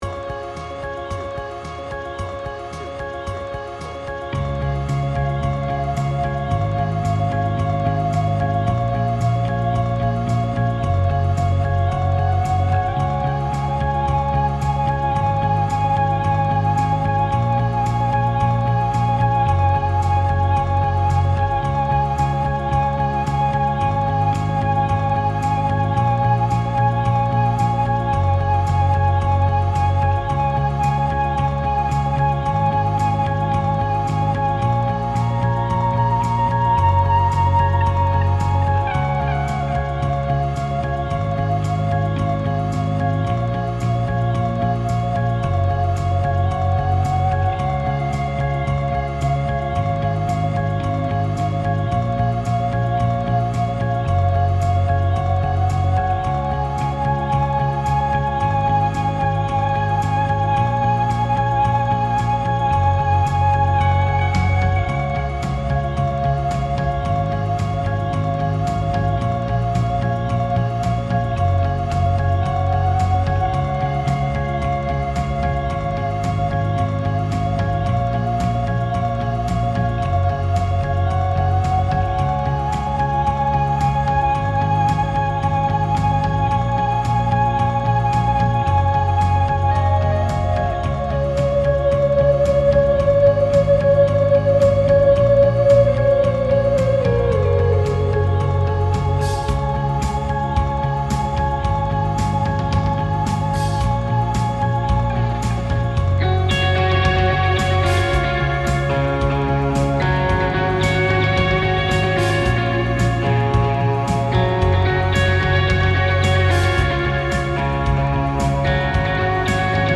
BPM : 111
Tuning : Eb
Without vocals